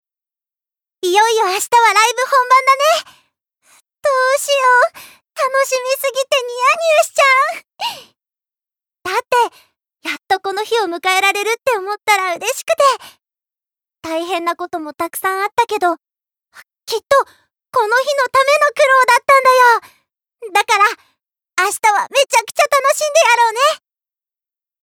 Voice Sample
ボイスサンプル
セリフ２